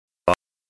※音素から0.05秒〜0.06秒だけを切り出しています。
使用した音声はthe speech accent archiveenglish1です。
音声は文"and maybe a snack for her brother Bob"の単語"Bob"の"o"でした。
（１）〜（４）の母音とは異なり、この母音だけ口の中で音がしていないことを感じられますか？
（１）〜（４）の母音より鼻で響いているのを感じられますか？